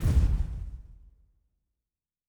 Fantasy Interface Sounds
Special Click 23.wav